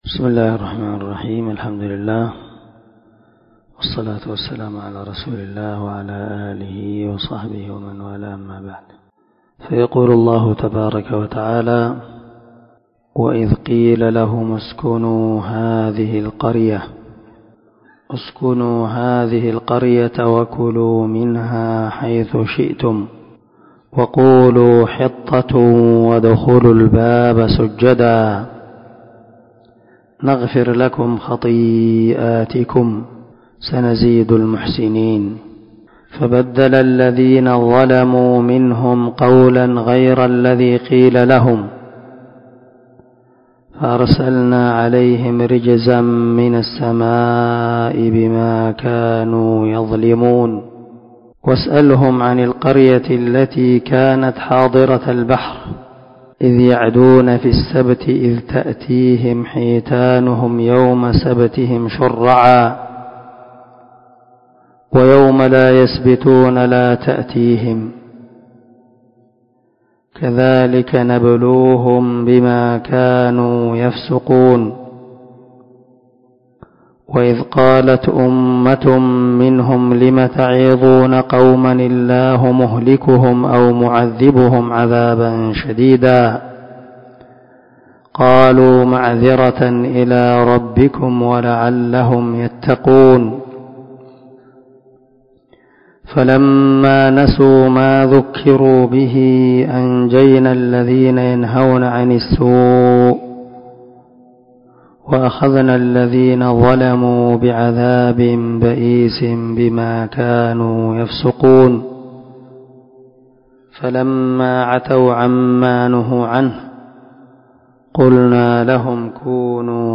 490الدرس 42تفسير آية ( 161 – 166 ) من سورة الأعراف من تفسير القران الكريم مع قراءة لتفسير السعدي